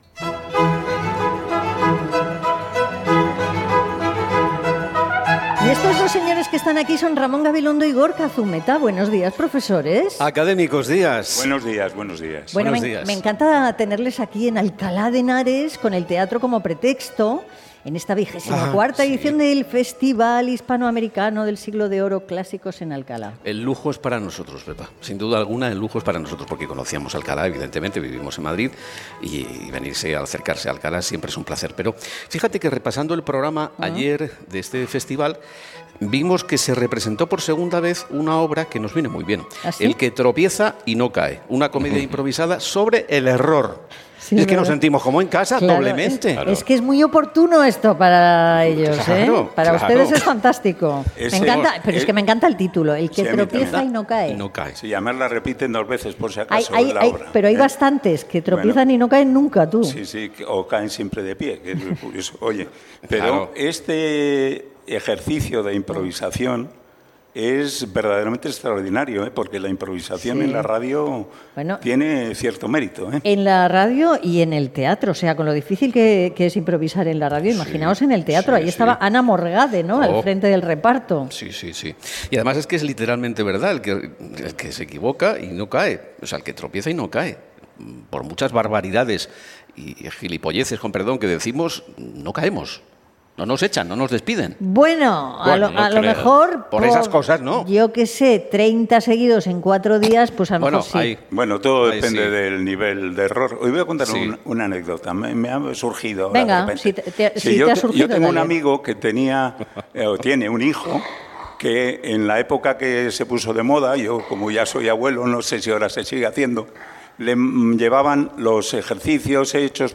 Programa fet des d'Alcalá de Henares. Secció "El estupidiario" amb un recull d'errades en declaracions, discuros, etc.